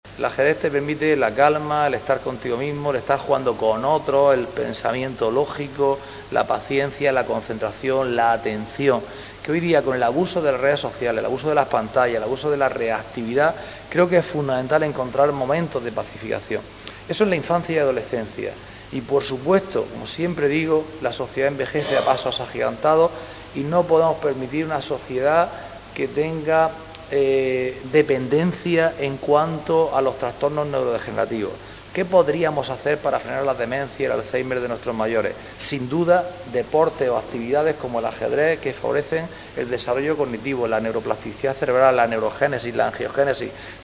CORTE-ANTONIO-JESUS-CASIMIRO-CONCEJAL-DEPORTES.mp3